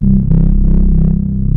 OSCAR PAD 01 1.wav